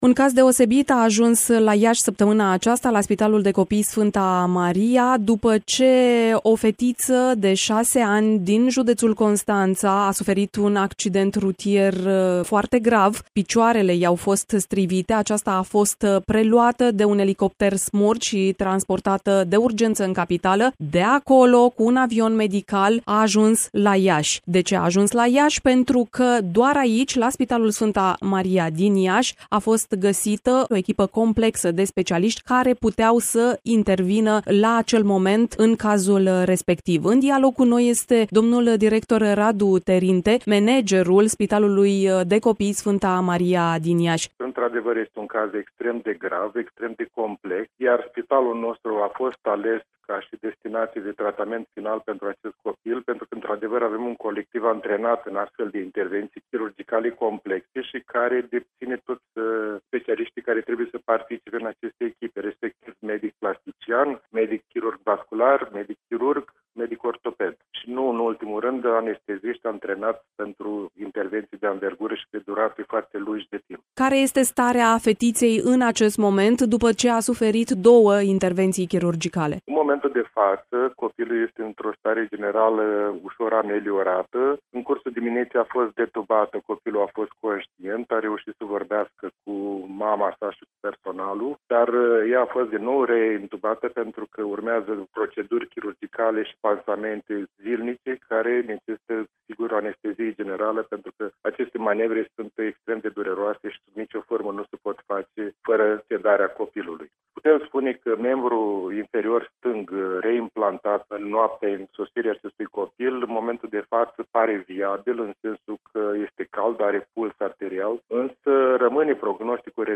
(INTERVIU) La Spitalul „Sfânta Maria” din Iași sunt operați